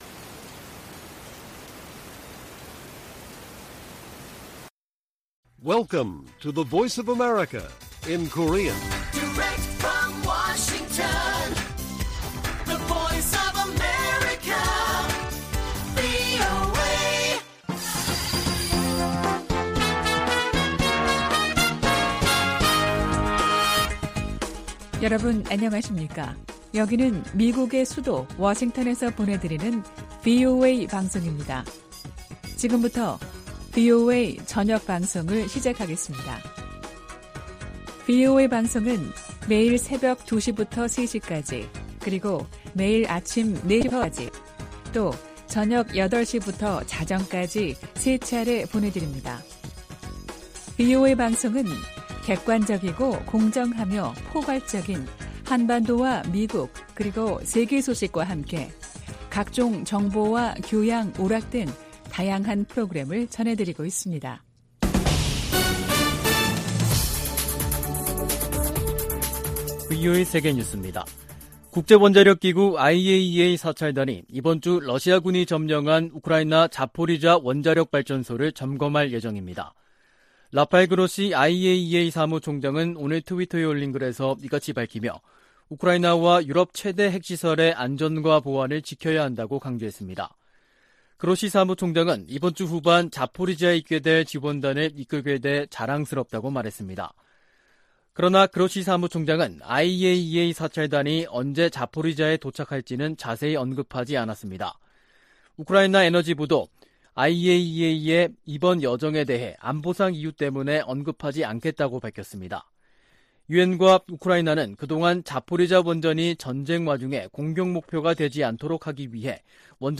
VOA 한국어 간판 뉴스 프로그램 '뉴스 투데이', 2022년 8월 29일 1부 방송입니다. 북한이 핵실험 준비를 마치고 한국을 향해 보복성 대응을 언급하고 있다고 한국 국방부 장관이 말했습니다. 제10차 핵확산금지조약 (NPT) 평가회의가 러시아의 반대로 최종 선언문을 채택하지 못한 채 끝났습니다. 호주가 주관하는 피치블랙에 처음 참가하는 일본은 역내 연합훈련이 다각적이고 다층적인 안보 협력 추진으로 이어진다고 밝혔습니다.